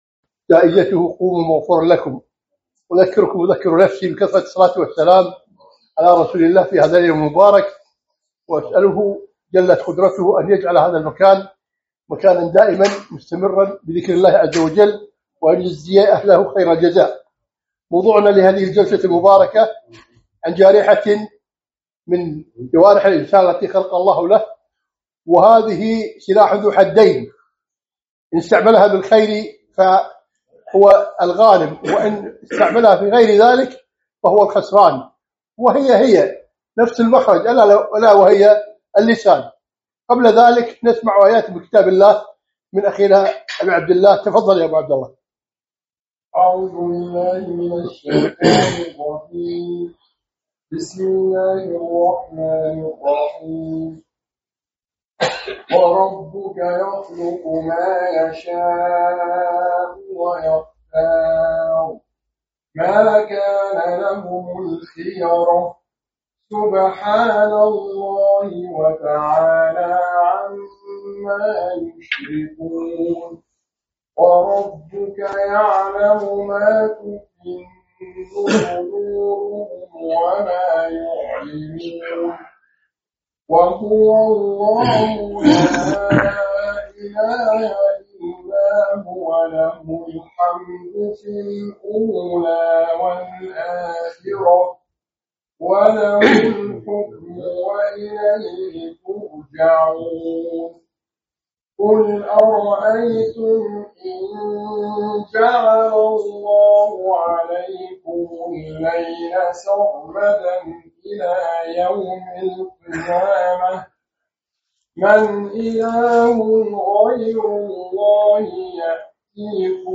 كلمة - أمسك عليك لسانك